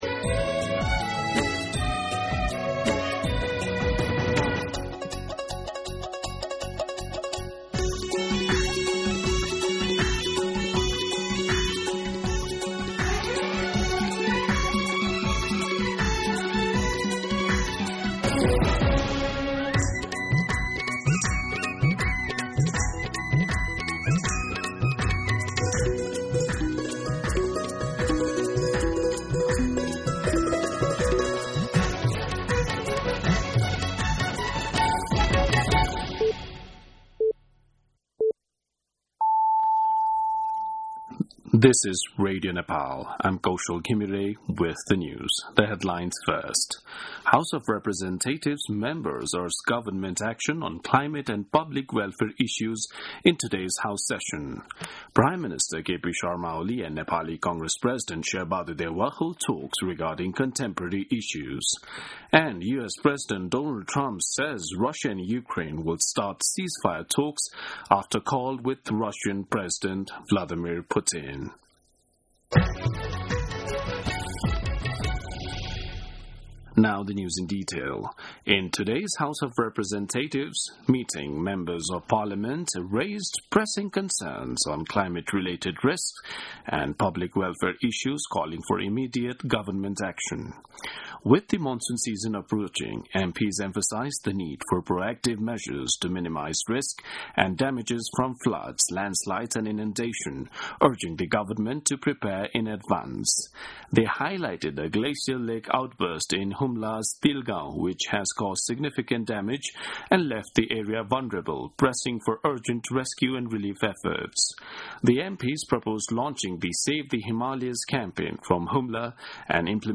An online outlet of Nepal's national radio broadcaster
दिउँसो २ बजेको अङ्ग्रेजी समाचार : ६ जेठ , २०८२